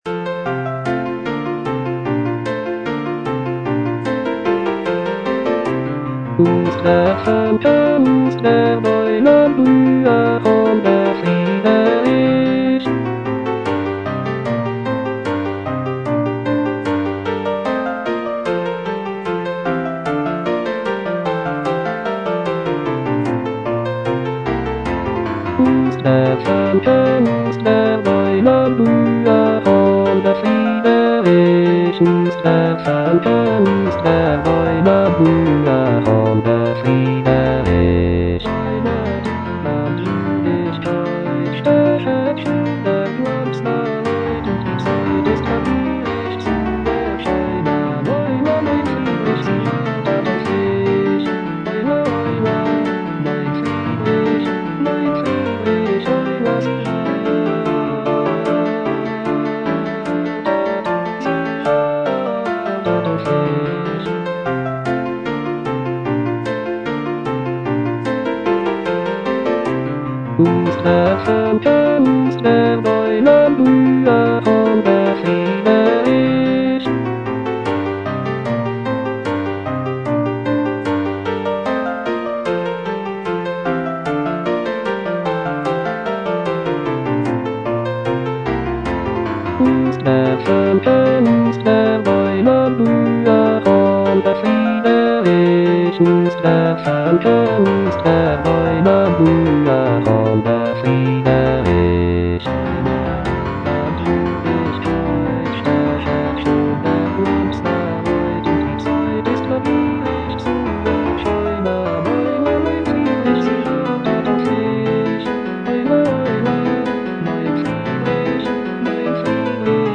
Choralplayer playing Cantata
The cantata features a celebratory and joyful tone, with arias and recitatives praising the prince and his virtues.